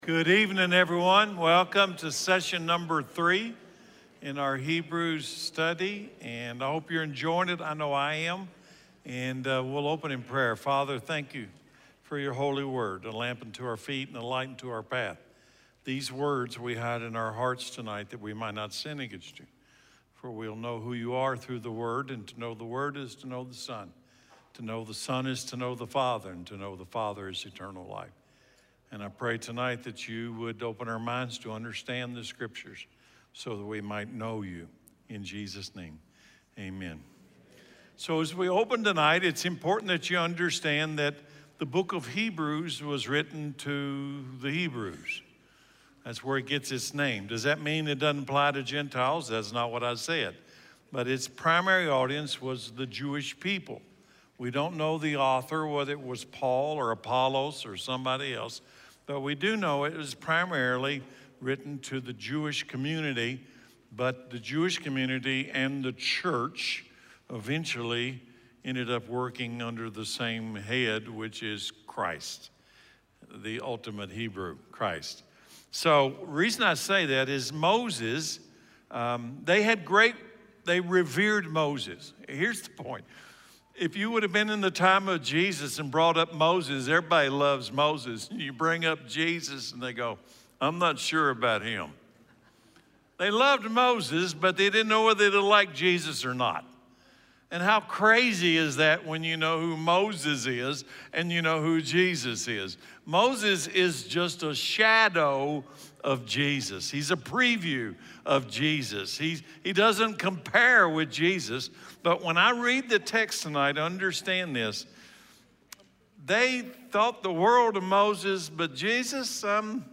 Hebrews, Session 3, Roots Class